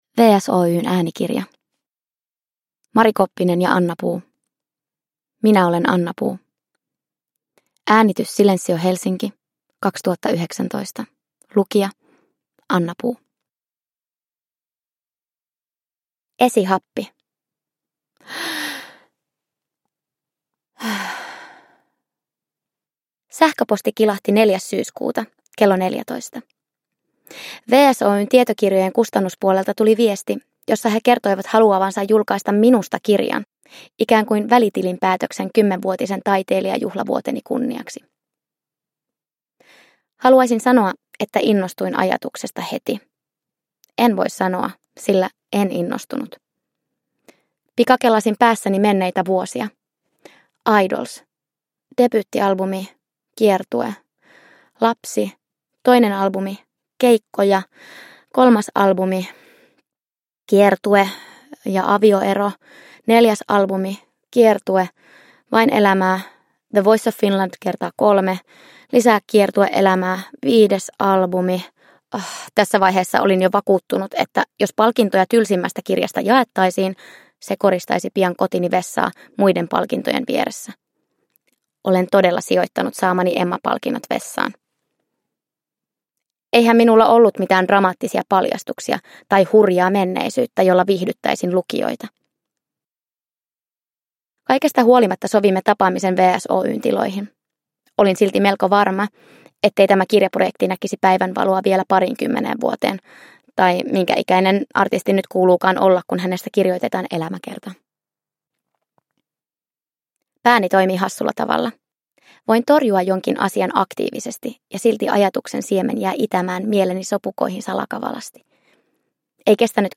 Ainutlaatuisilla varhaisilla demoversioilla äänikuvitettu suosikkiartistin kasvutarina.
Uppläsare: Anna Puu